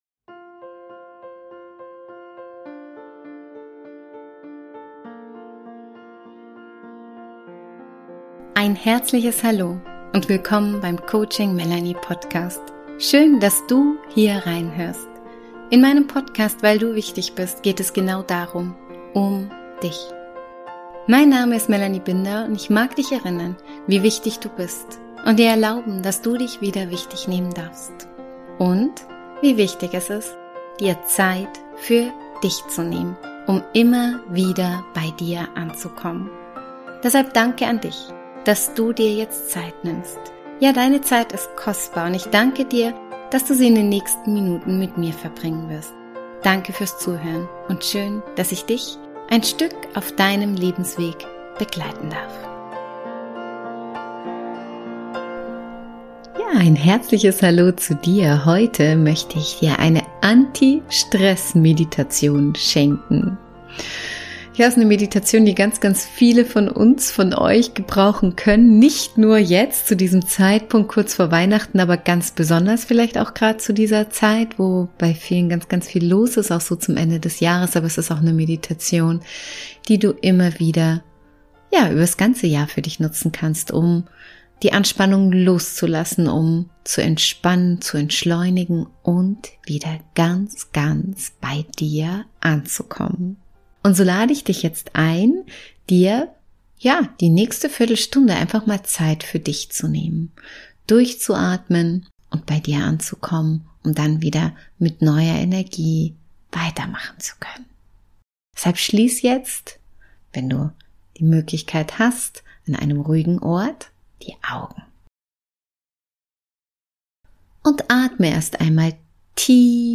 Dies ist eine Meditation, die dir in stressigen Zeiten helfen kann, zu entschleunigen – also nicht nur vor Weihnachten.